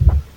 Tags: 808 drum cat kick kicks hip-hop